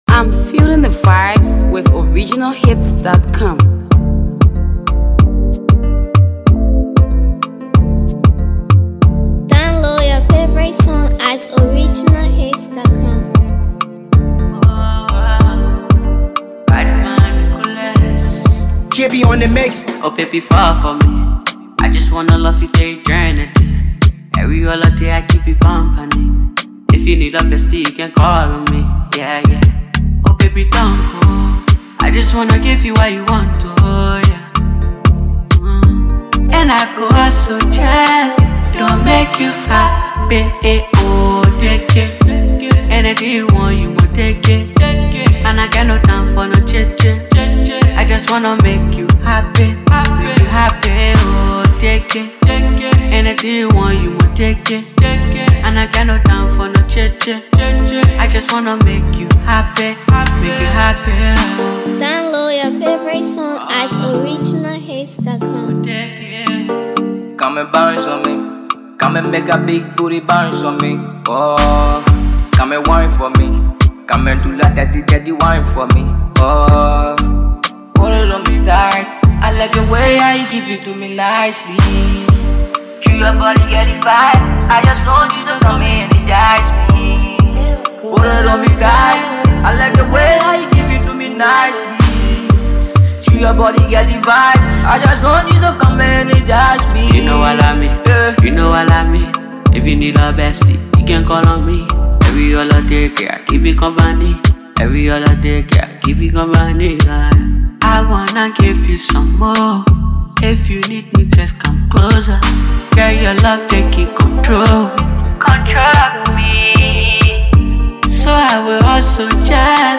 who is well recognized for his nice vocal
afro fresh tune